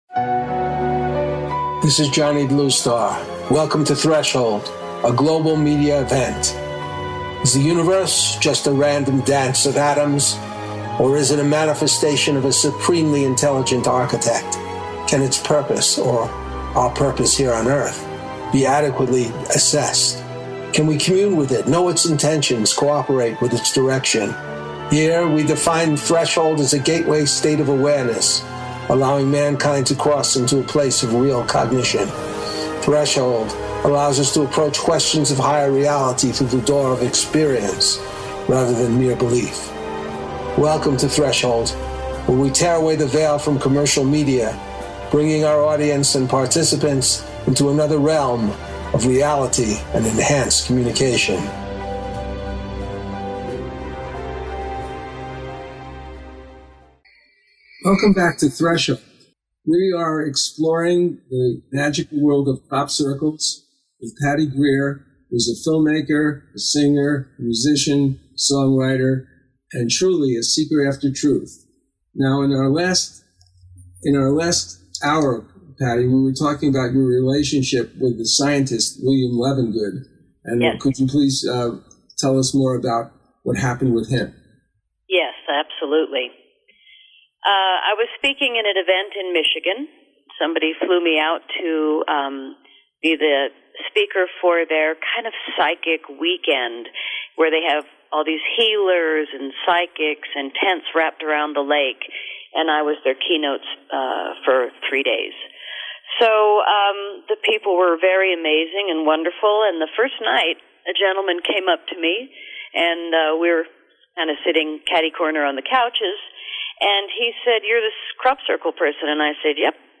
Threshold Radio is a radio talk show postulating that personal connection to a Higher Power is the most profitable way of being connected to your life, your destiny and to the Source whose purpose is embodied in the nature of things.